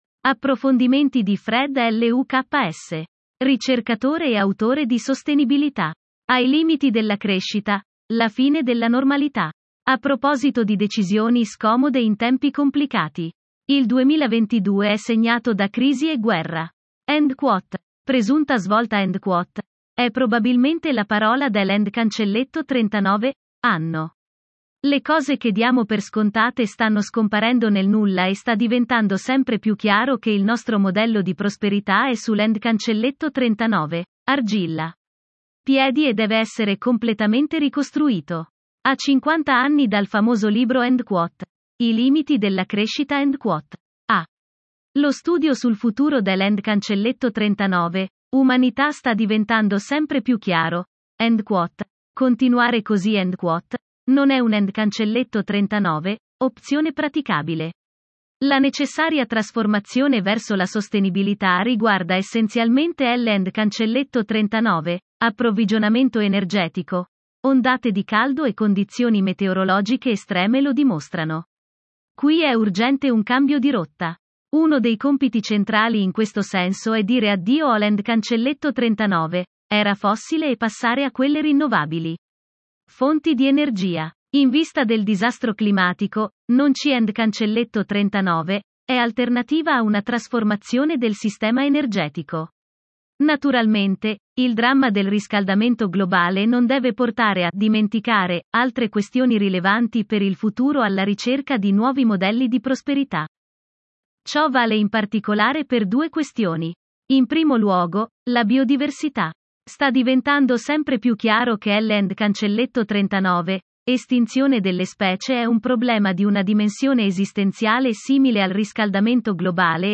Einsichten Deutsch Weiblich Schwedisch Weiblich English Weiblich Italian Weiblich 2 views Share Download How was the audio ?